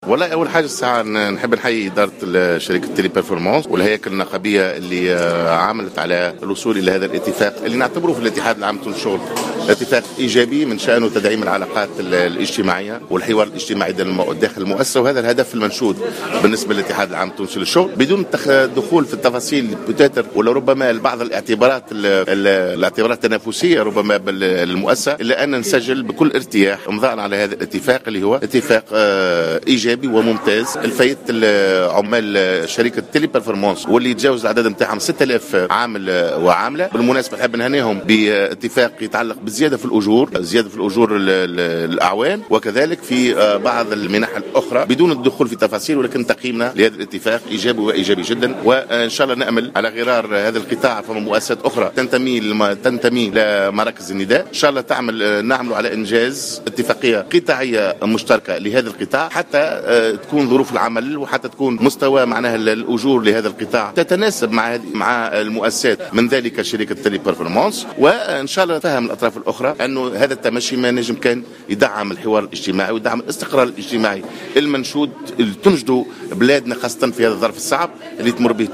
dans une déclaration aux médias